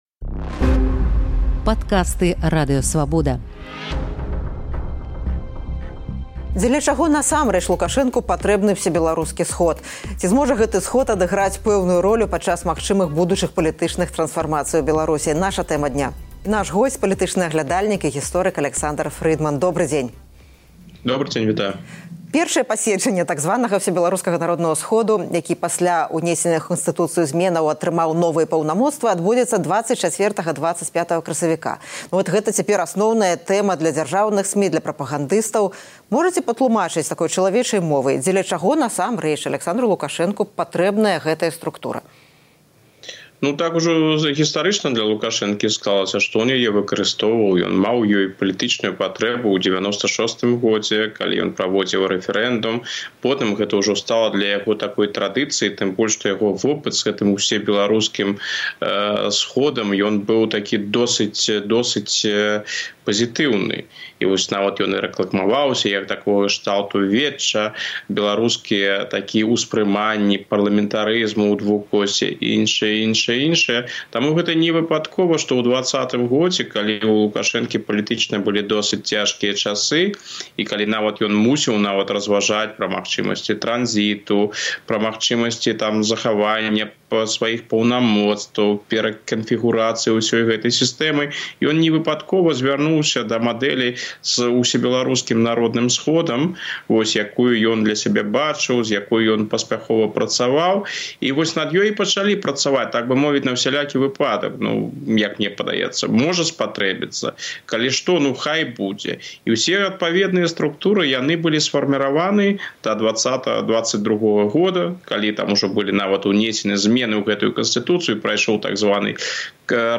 Навошта Лукашэнку Ўсебеларускі народны сход? Тлумачыць палітоляг